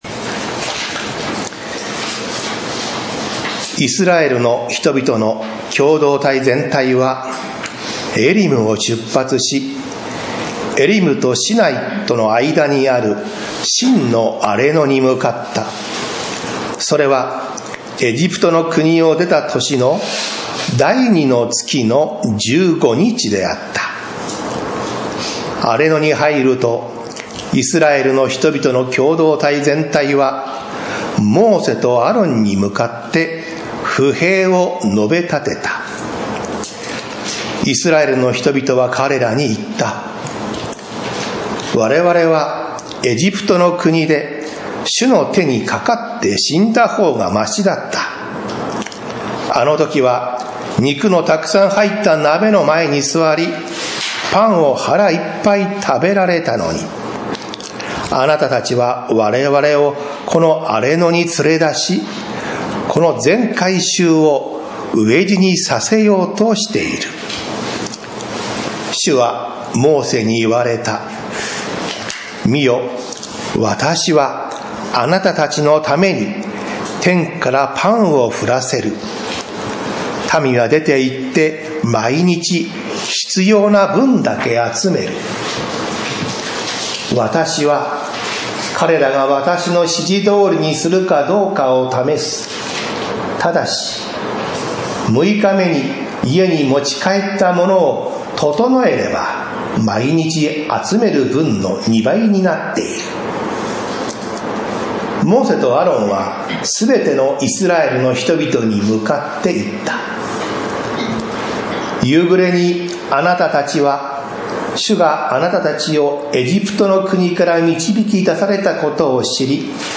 不平や不満をつぶやくとき 宇都宮教会 礼拝説教